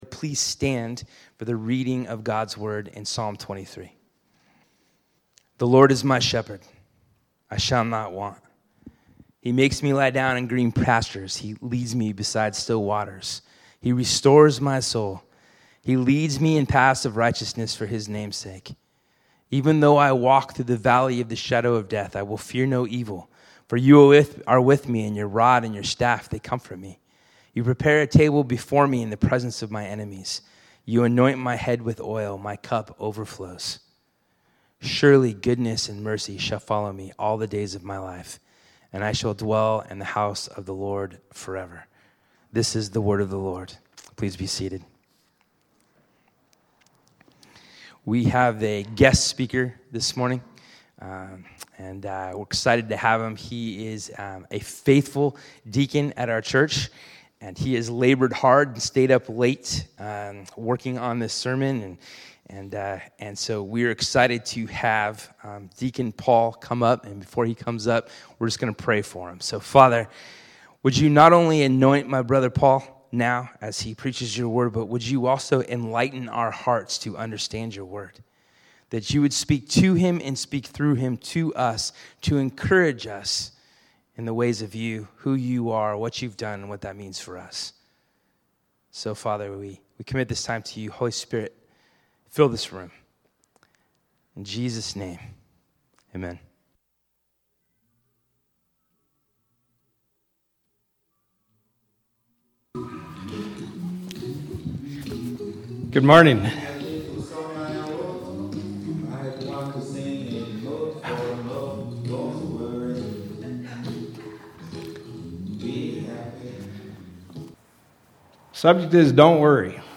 Sermon Slides